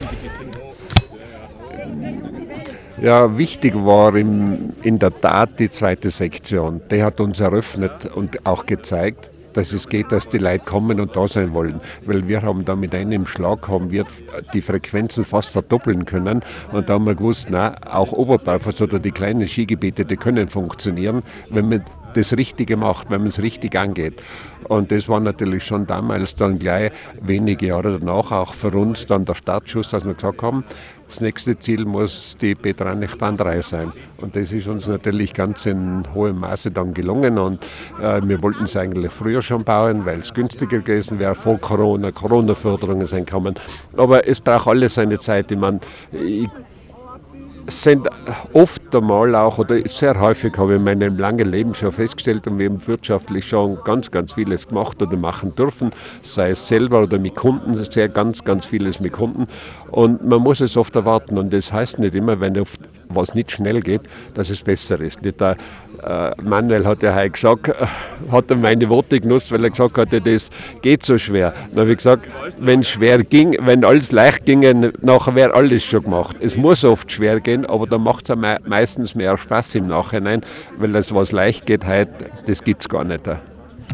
Drohnenflug über das Rangger Köpfl während der Eröffnung der neuen Kombibahn Peter Anich III.